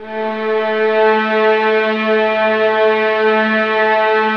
VIOLINS BN-R.wav